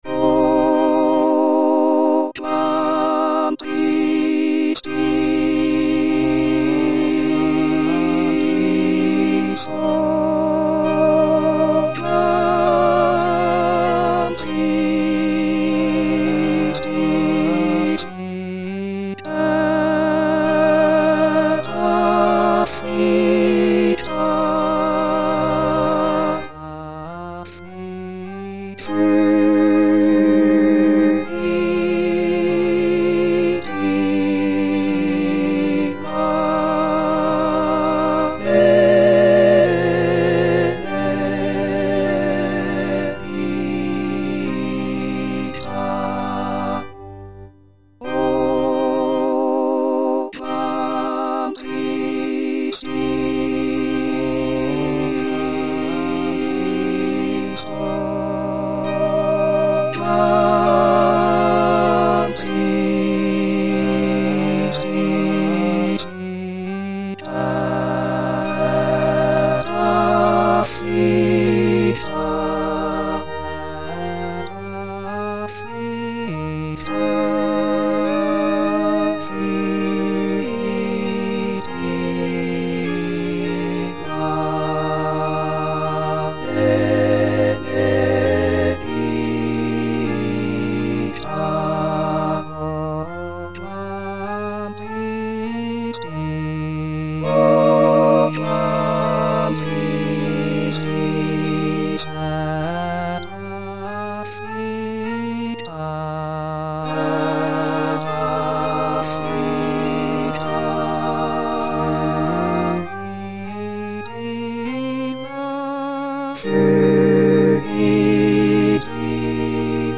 Parole 3: Mulier, ecce filius tuus        Prononciation gallicane (à la française)
Tutti